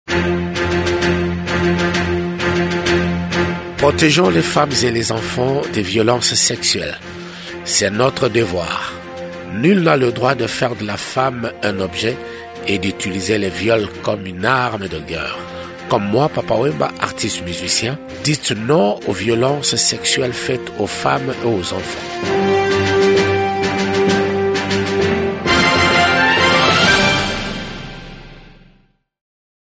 Ecoutez ici les messages de Papa Wemba, chanteur et leader d’opinion congolais, à l’occasion de la campagne de lutte contre les violences faites aux femmes et aux enfants: